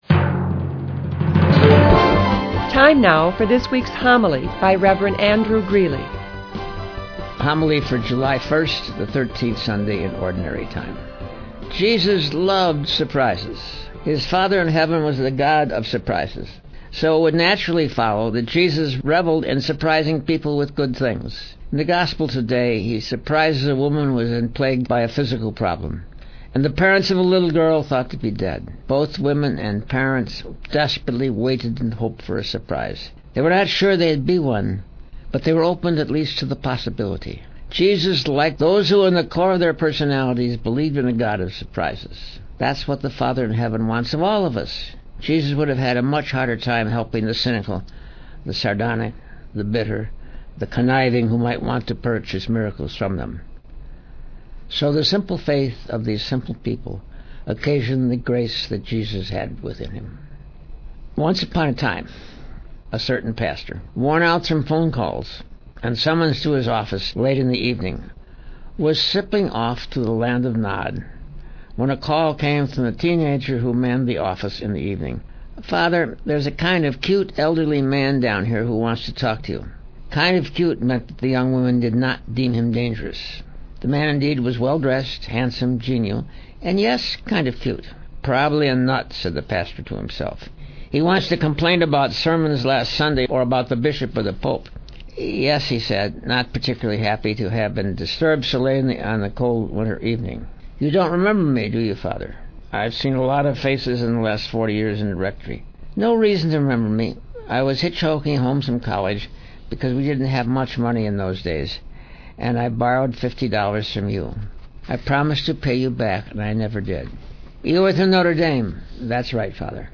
Father Greeley has started doing his Homilies on Revelant Radio WCSN 820 AM.
The Latest Audio Homilies from the Archdiocese of Chicago (mp3)
Homily 07.01.2007: Aired: 07/01/2007;